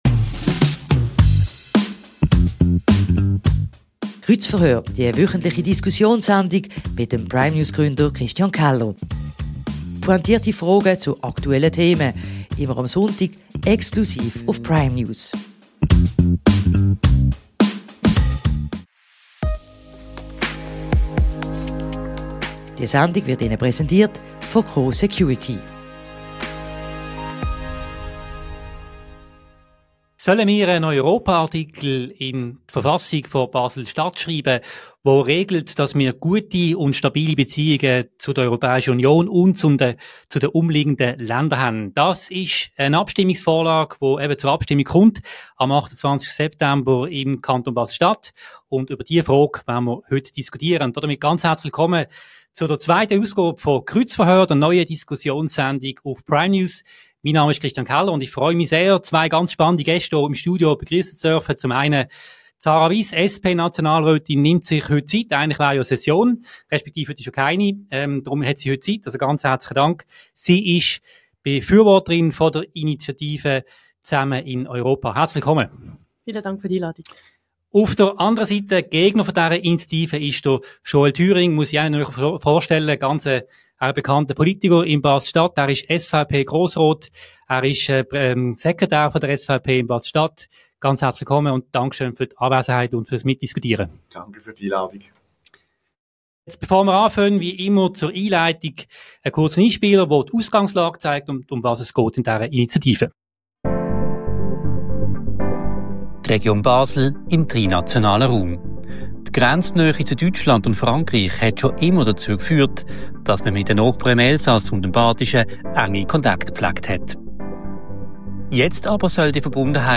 Europa-Artikel in der Basler Verfassung? Darüber diskutieren SP-Nationalrätin Sarah Wyss und SVP-Grossrat Jöel Thüring.